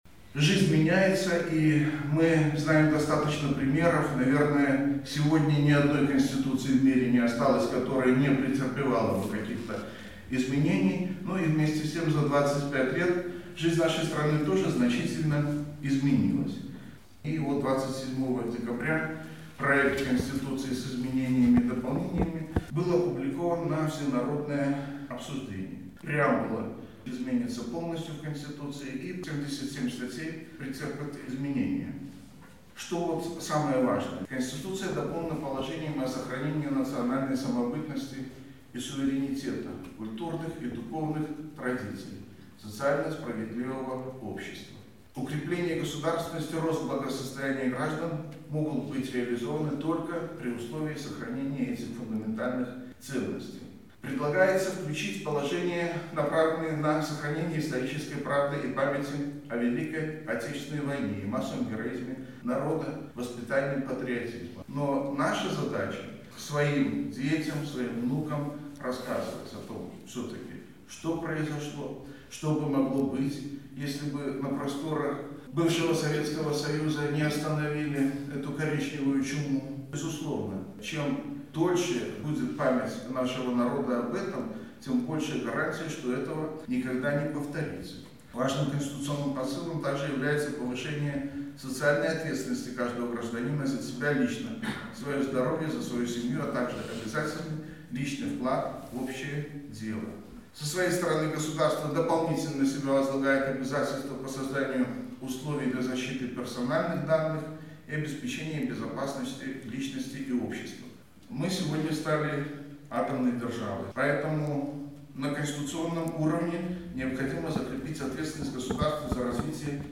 Председатель Барановичского горисполкома на встрече с работниками соцзащиты говорил о проекте обновленной Конституции (+AUDIO)